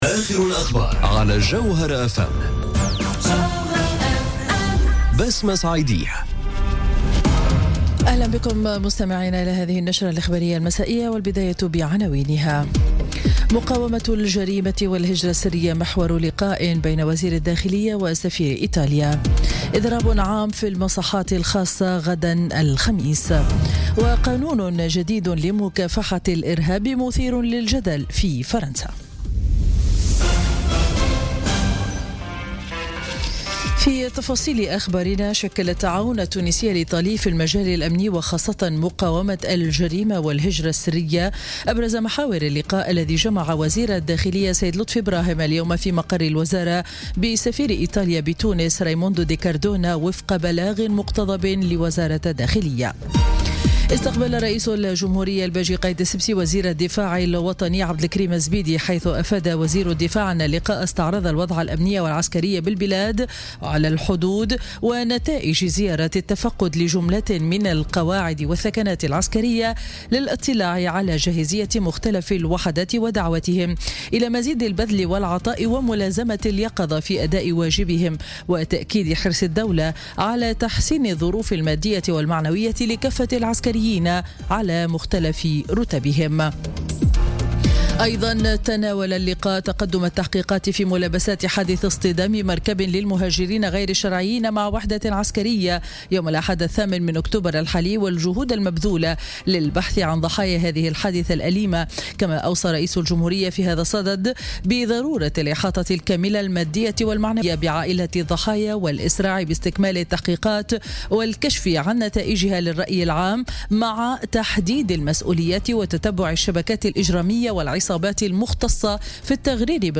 نشرة أخبار السابعة مساء ليوم الأربعاء 18 أكتوبر 2017